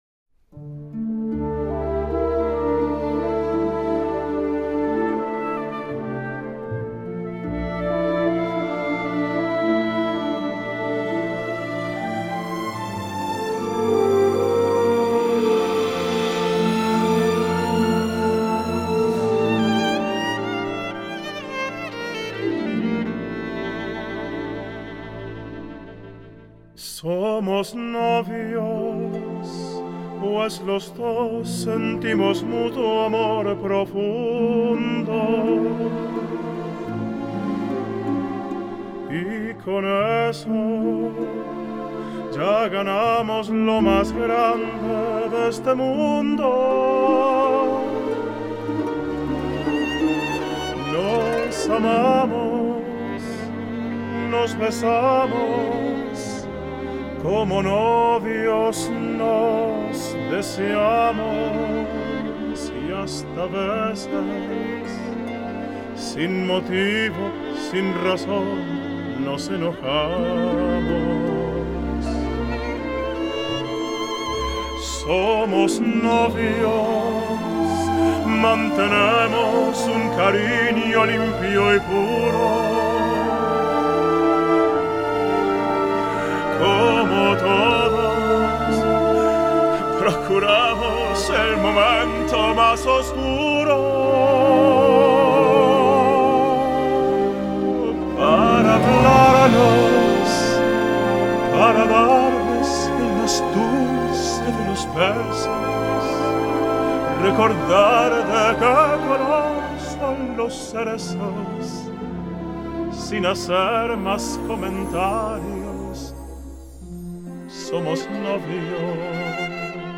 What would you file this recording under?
Genre: Latin